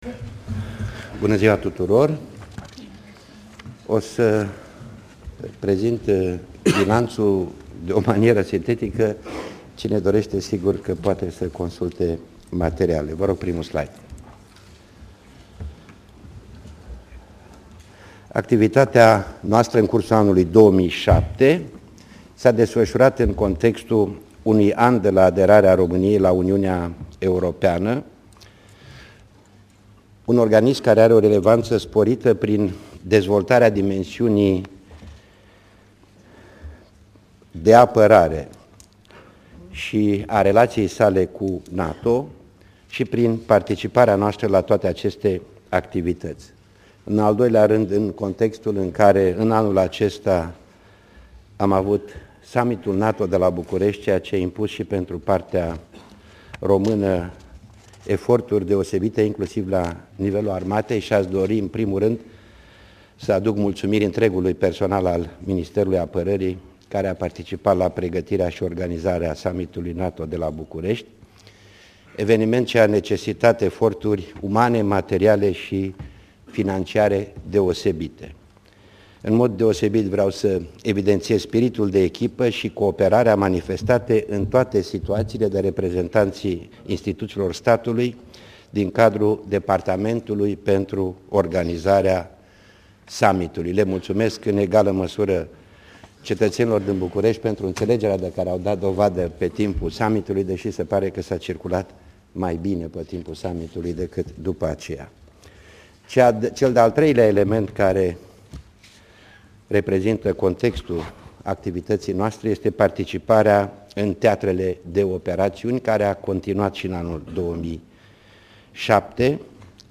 Aspecte de la ceremonial, Analiza activitatii M.Ap., Discursul ministrului apararii, Teodor Melescanu, Discursul premierului Calin Popescu Tariceanu, Discursul presedintelui Traian Basescu